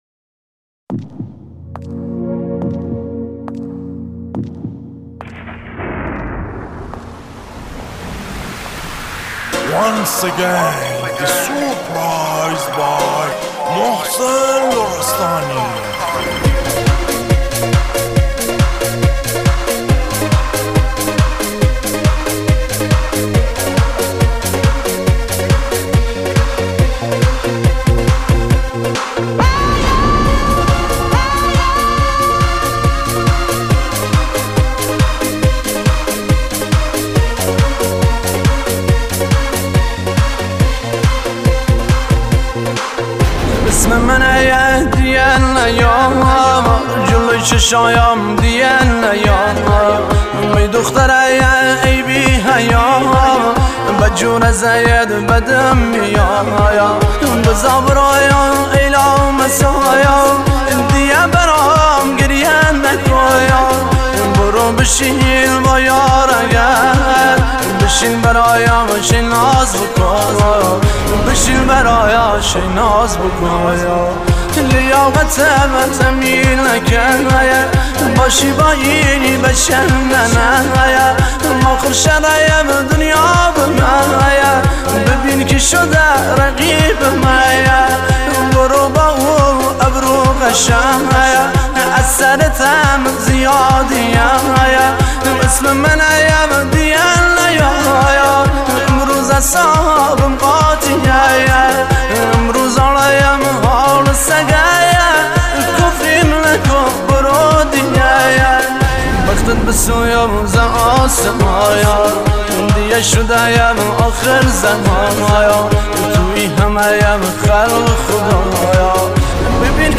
یکی از خوانندگان کردی اهل شهر کرمانشاه
آهنگ زیبای کردی